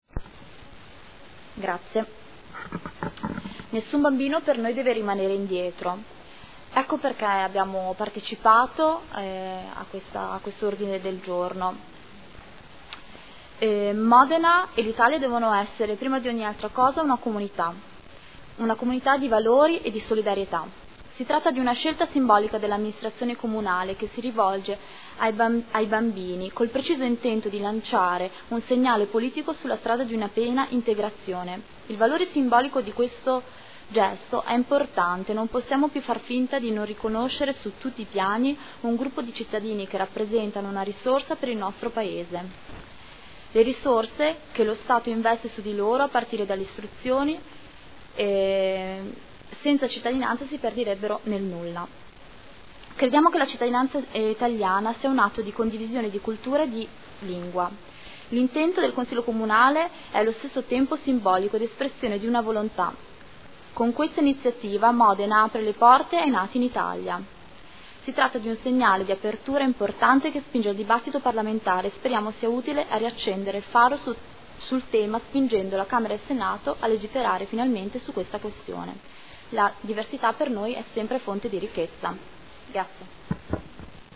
Elisabetta Scardozzi — Sito Audio Consiglio Comunale